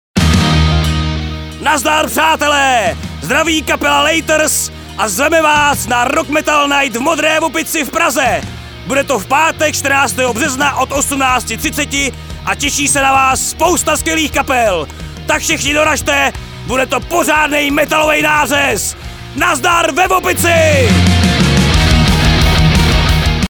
AUDIOPOZVÁNKA